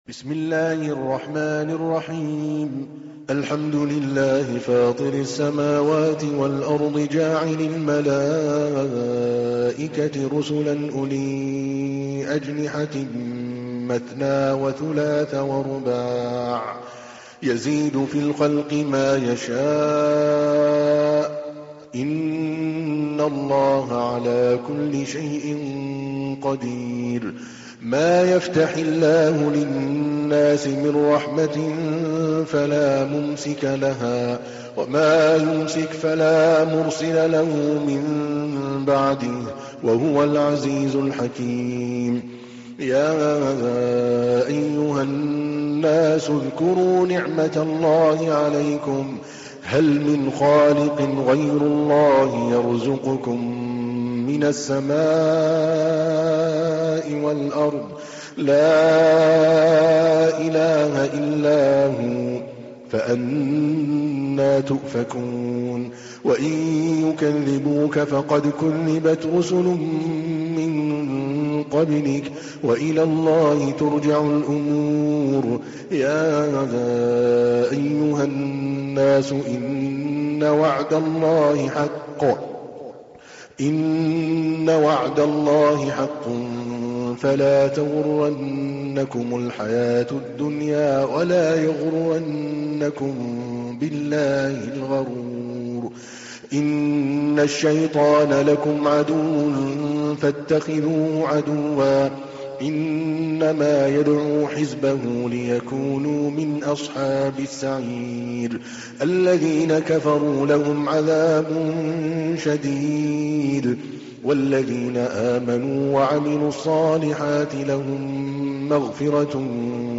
تحميل : 35. سورة فاطر / القارئ عادل الكلباني / القرآن الكريم / موقع يا حسين